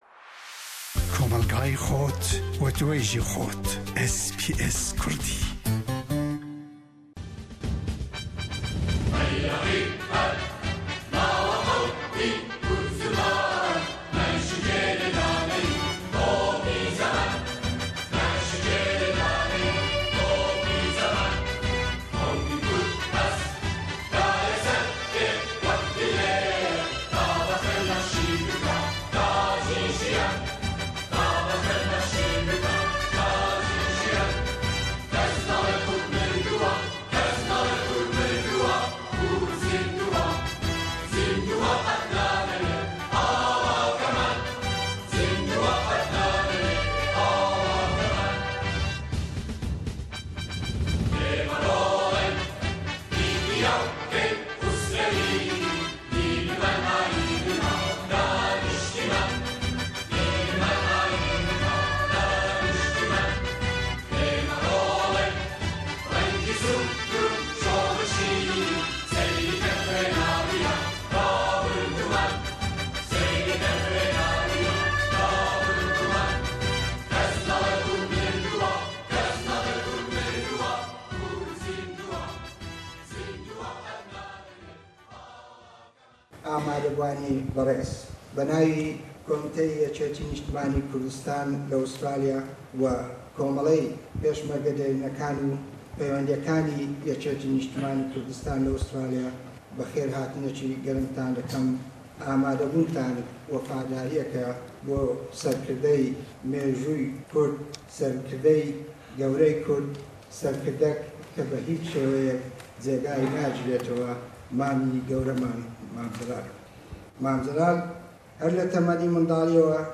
SBS kurdî roja Yekshema berê li bajarê Sydney li devera Olympic Park beshdare shîn û serxwoshiya Serokomarê Iraqê yê berê û Sekreterê Gishtî yê YNK mam Celal Talebanî bû.
Nûnerên partiyên siyasî, komeleyetî, kesetiyên ji civaka kurd û endamên civaka Kurd jî beshdar bûbûn, nêzika 300 kesî ji endamên civaka kurd beshdar bûbûn.